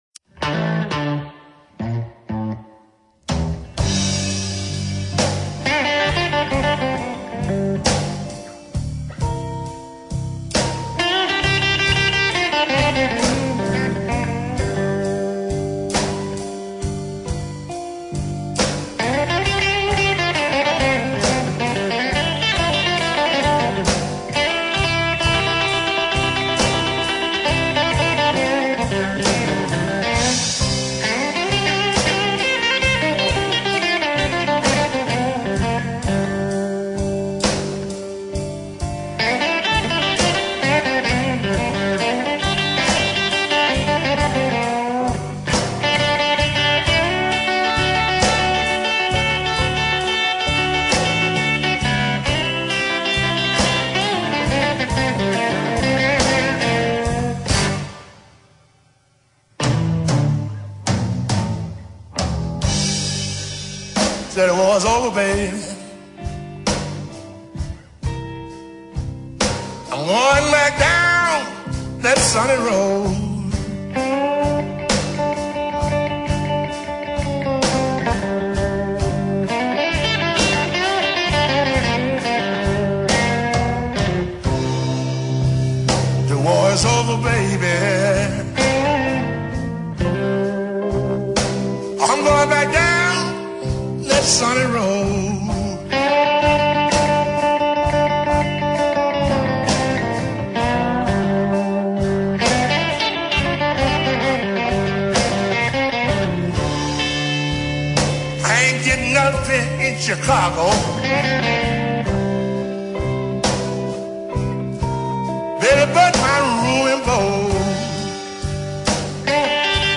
Jazz Blues Para Ouvir: Clik na Musica.